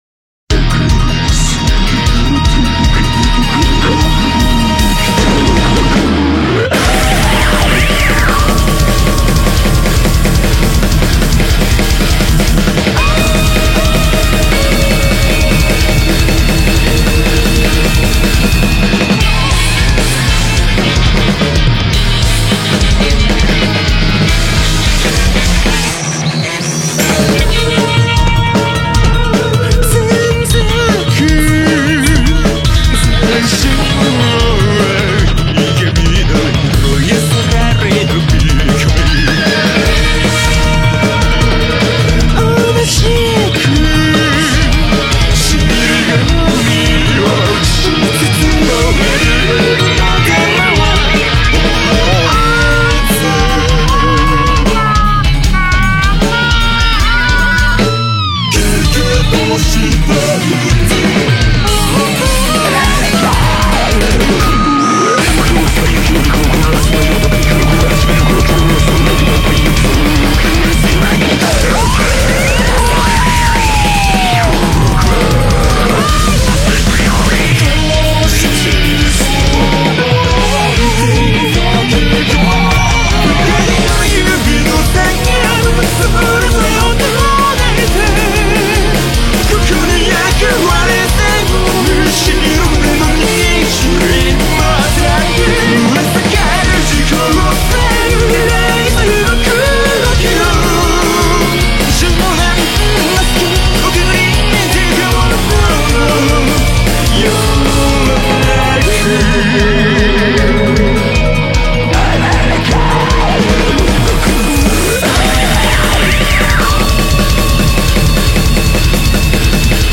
BPM154
Audio QualityPerfect (High Quality)